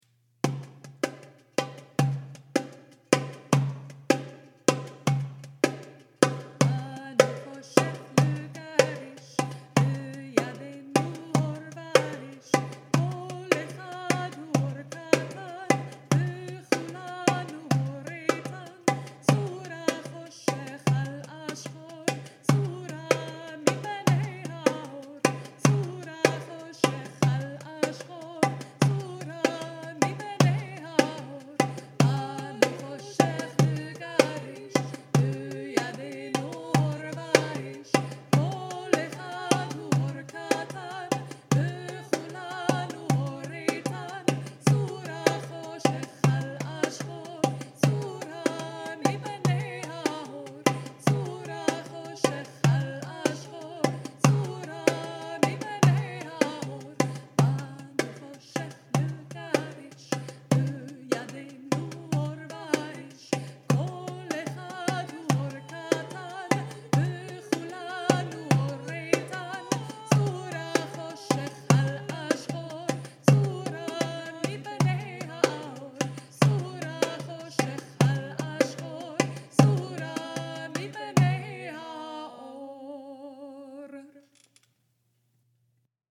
Banu choshech l’gareish, traditional Chanukah folksong
banu-choshech-lgareish-harmonized.mp3